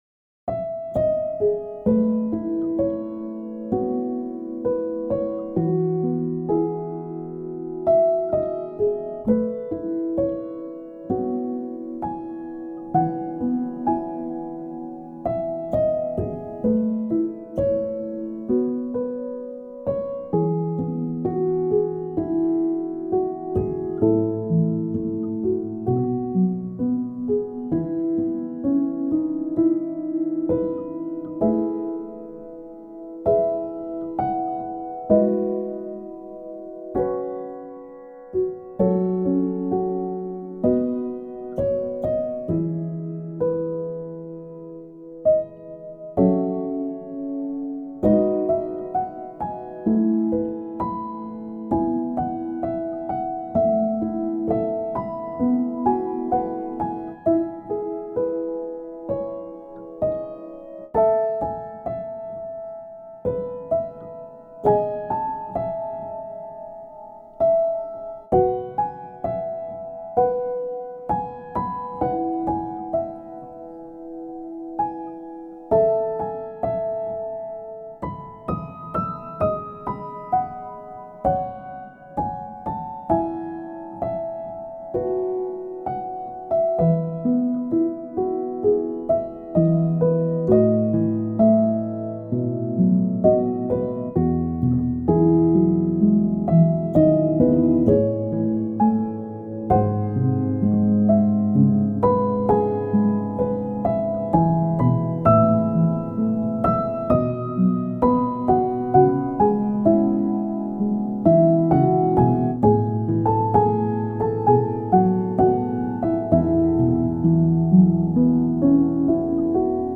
バラード ピアノ 儚い 切ない 悲しい 懐かしい 穏やか